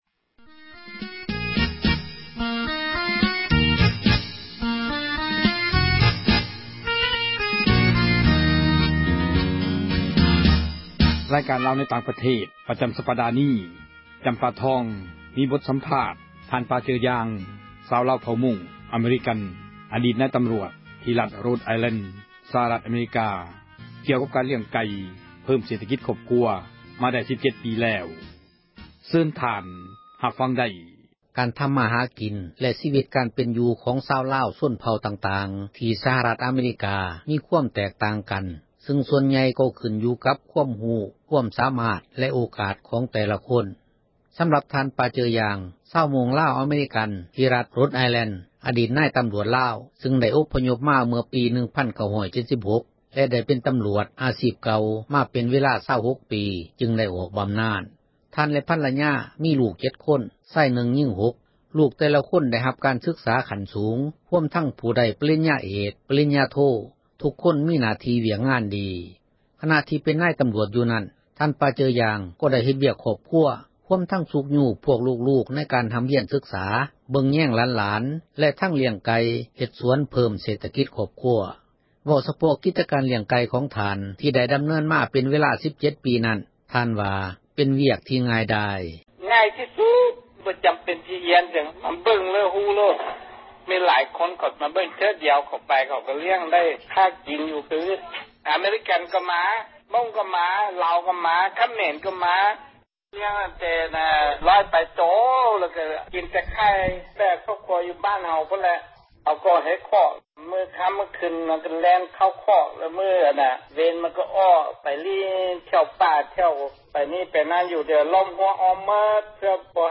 ການສຳພາດ